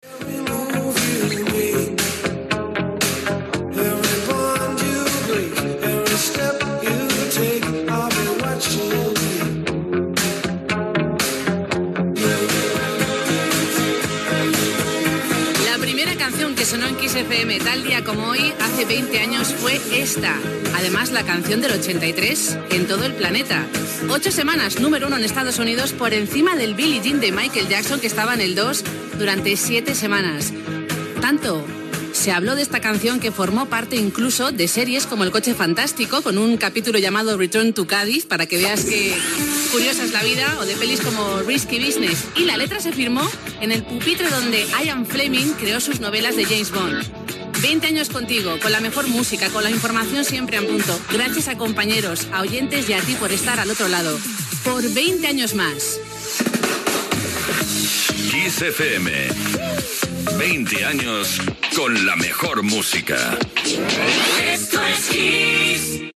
Primer tema musical que va sonar a Kiss FM feia 20 anys, comentari sobre ell i indicatiu dels 20 anys de la ràdio
Musical